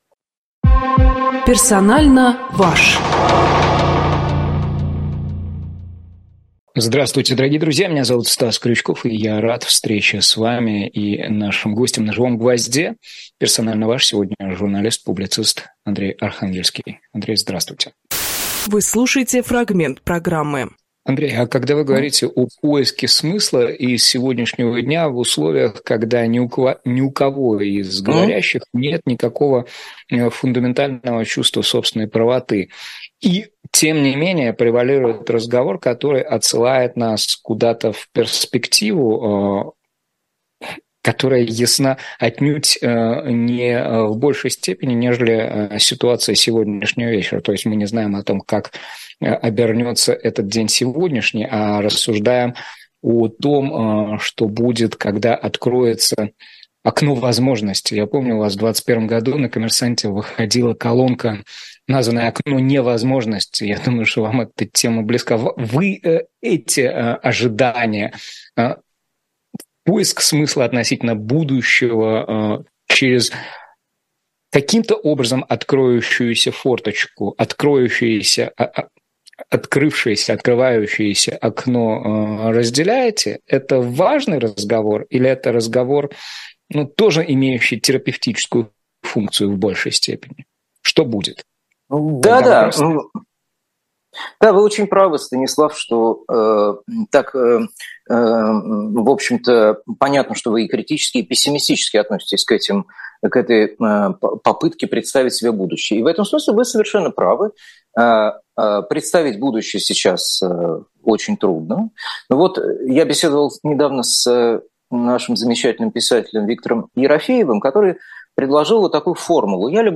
Фрагмент эфира от 13.11.23